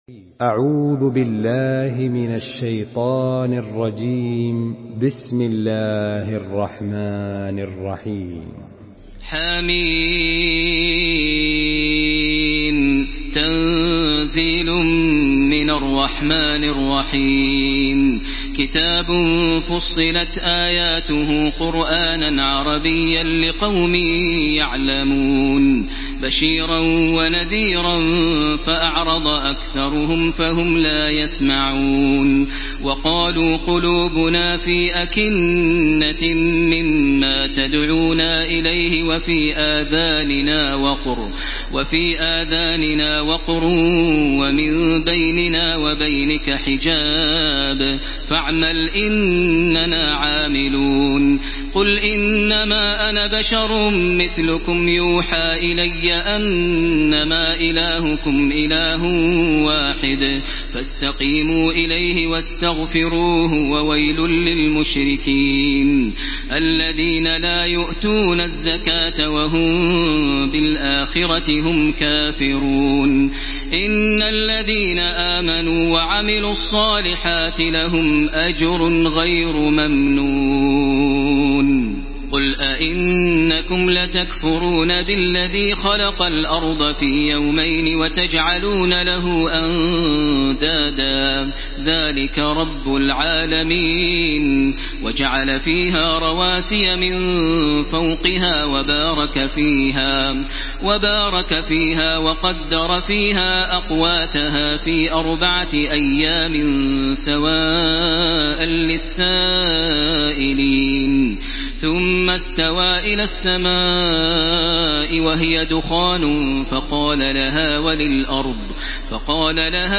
Surah ফুসসিলাত MP3 in the Voice of Makkah Taraweeh 1434 in Hafs Narration
Murattal Hafs An Asim